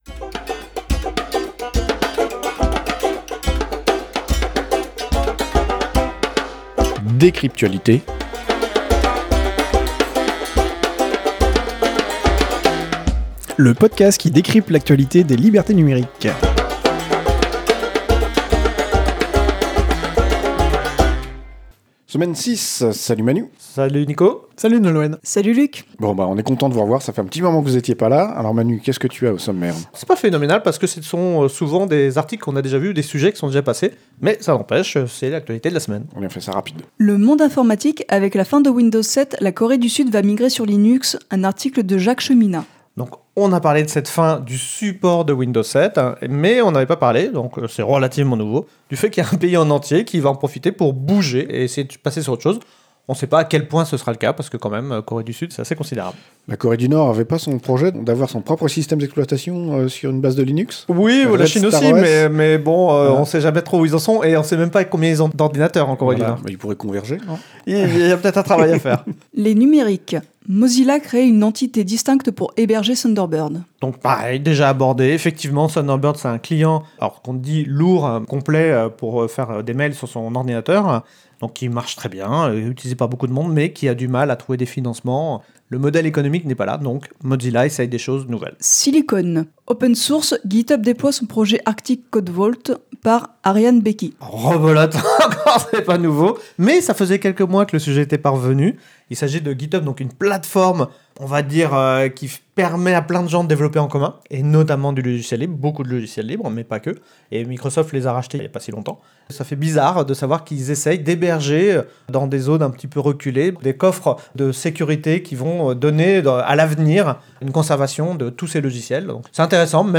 Studio d'enregistrement